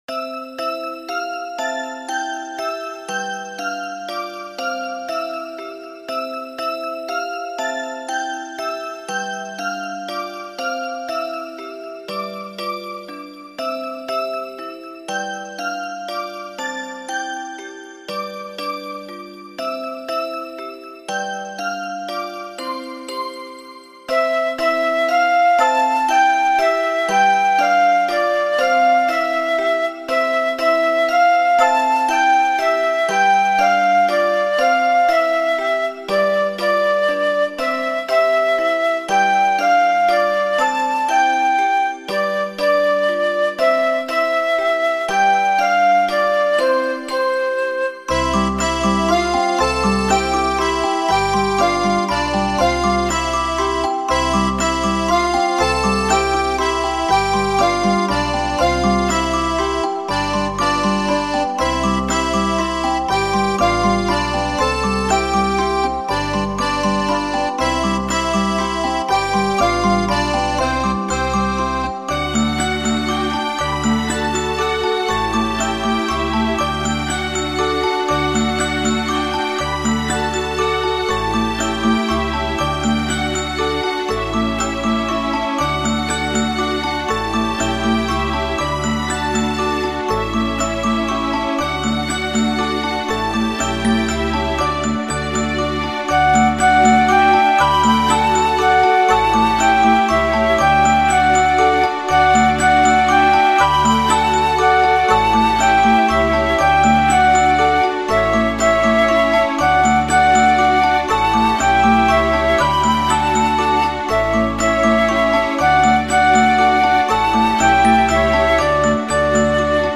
Аудиокнига Нам 1 годик | Библиотека аудиокниг
Прослушать и бесплатно скачать фрагмент аудиокниги